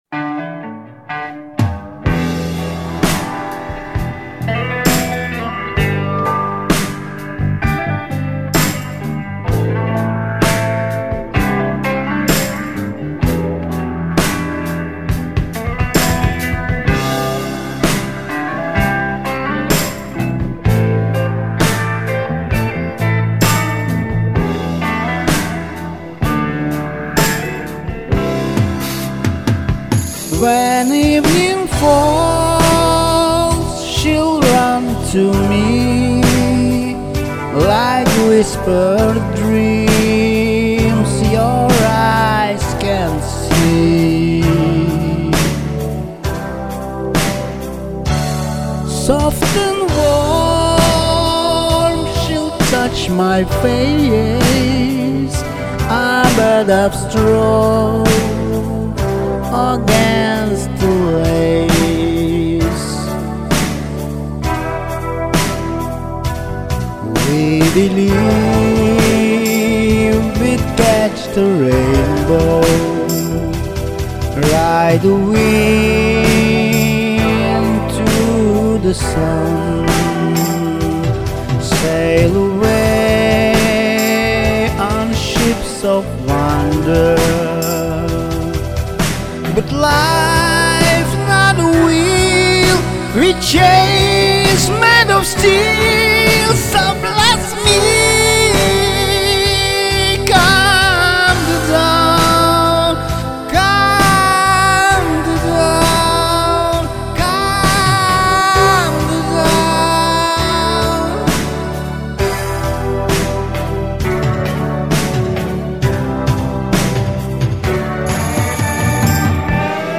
Яркий сочный голос .Звучок все же отмечу ,что слушабельный.